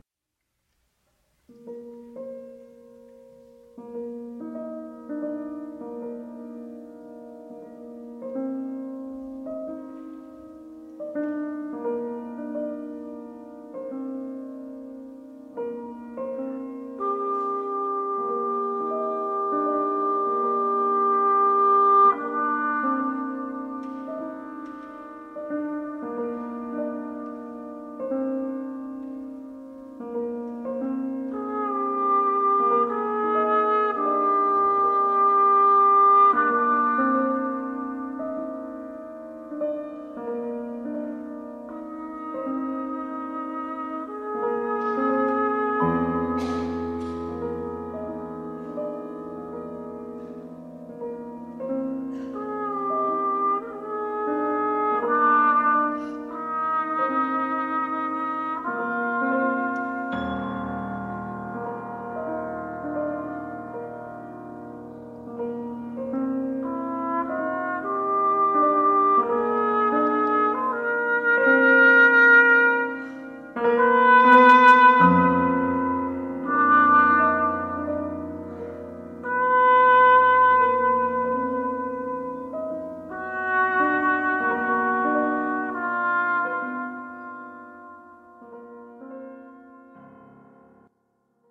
for trombone and piano